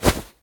fire2.ogg